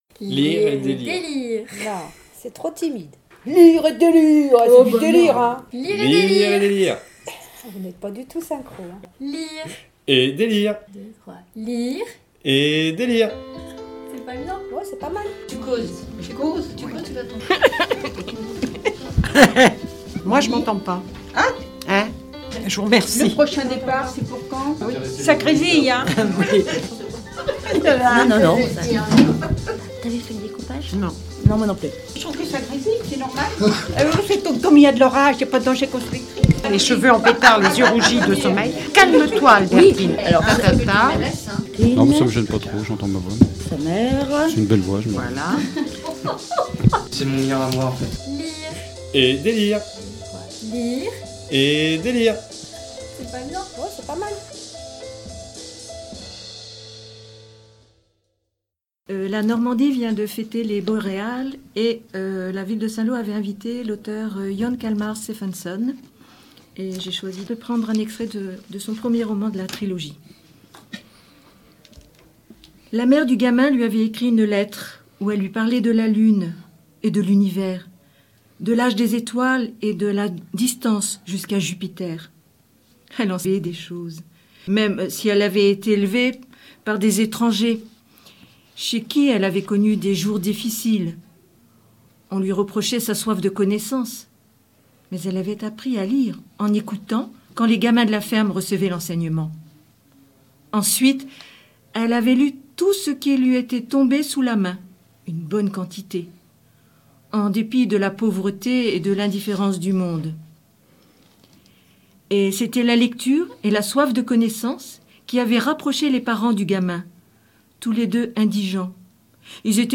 Les Haut Parleurs de l'association "Lire à Saint-Lô" prêtent leurs voix sur MDR dans une toute nouvelle émission "Lire et délire" !